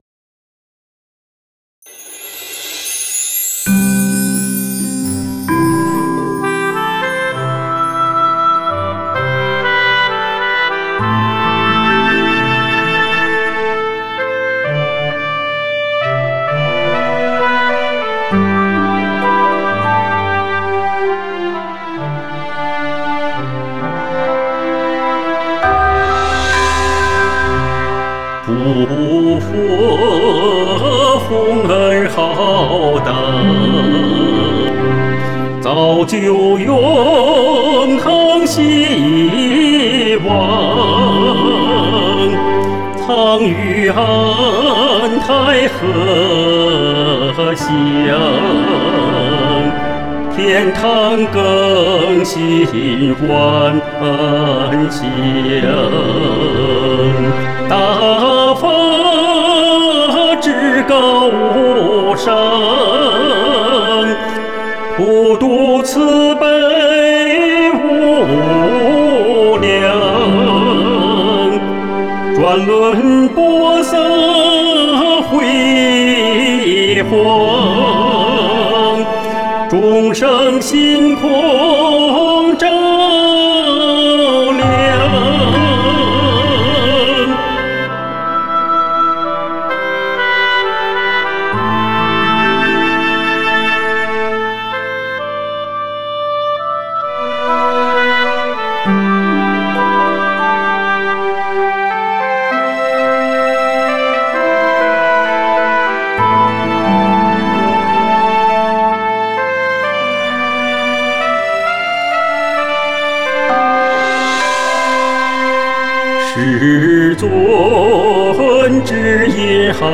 【慶祝513】男聲獨唱：大法至高無上 | 法輪大法正見網